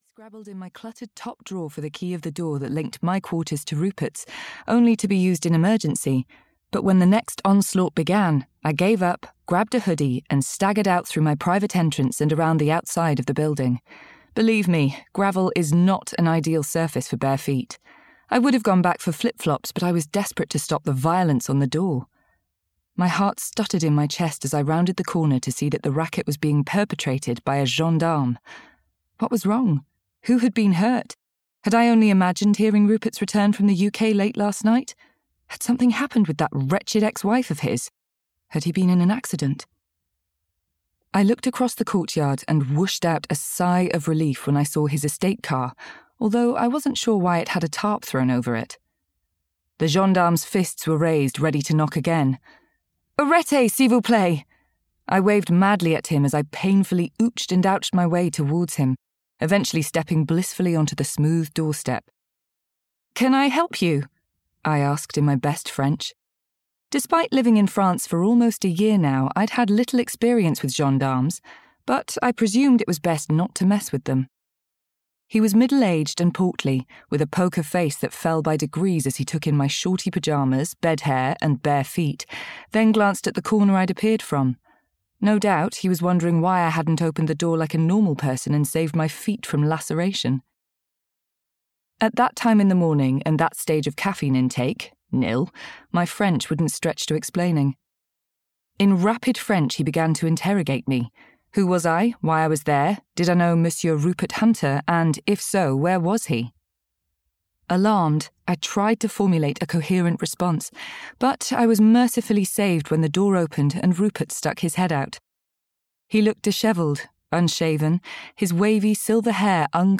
Summer at the Little French Guesthouse (EN) audiokniha
Ukázka z knihy
summer-at-the-little-french-guesthouse-en-audiokniha